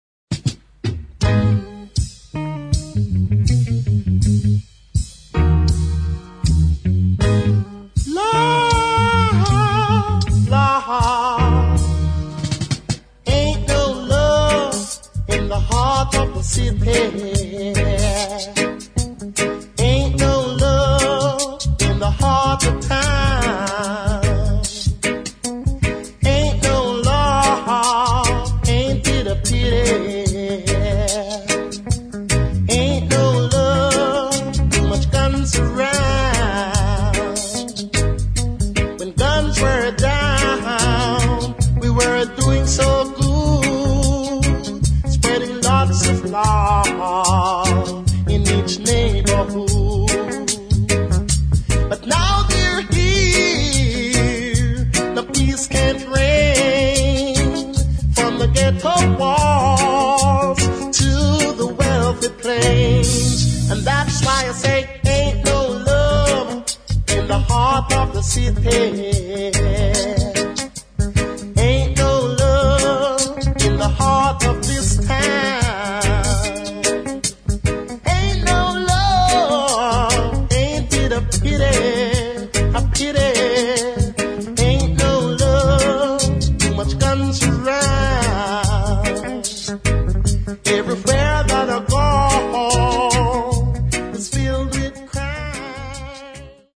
[ ROOTS REGGAE / DUB ]
ジャマイカン・カヴァーした名盤で